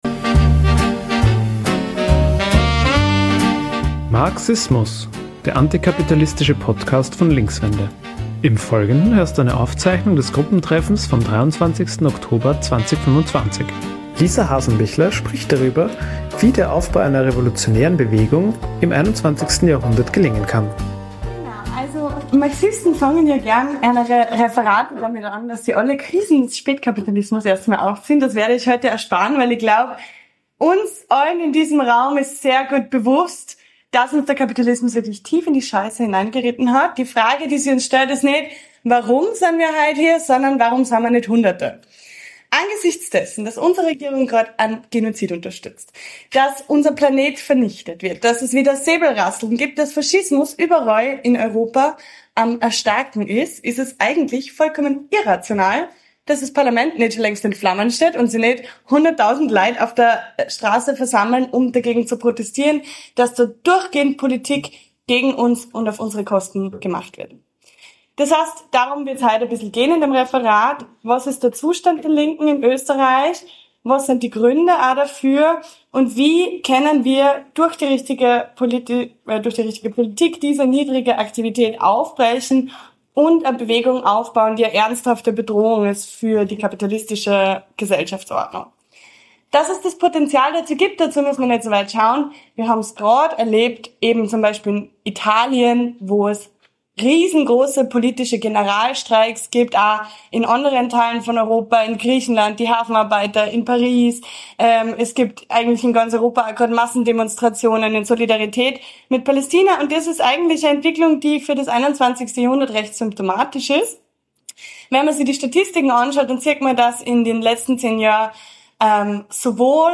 Diese Folge ist eine Aufzeichnung des Gruppentreffens vom 23. Oktober 2025 in Wien.